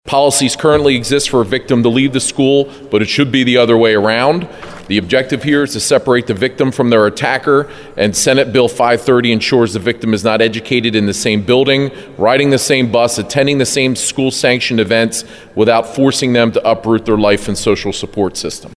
A bill to protect student sexual assault victims is before the state Senate. Republican Senator Scott Martin talks about what the bill would do.